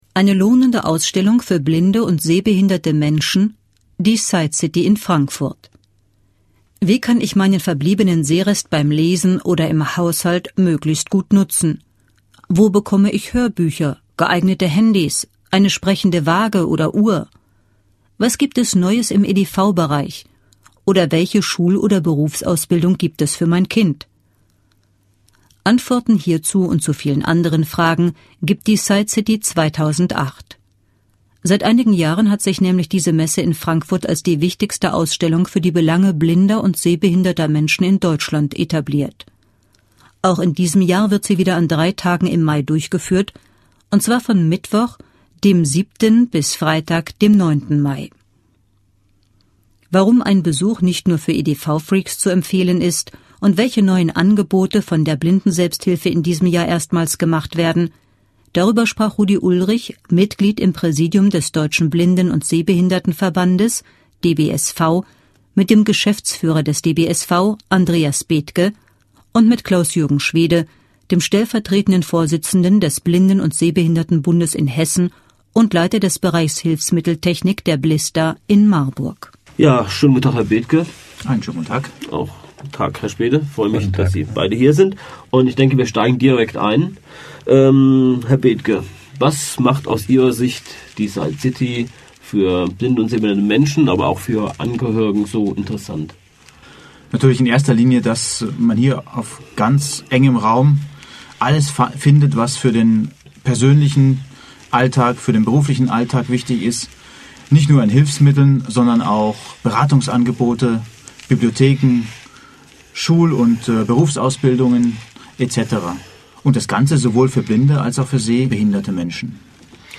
Sight City 2008 - Interview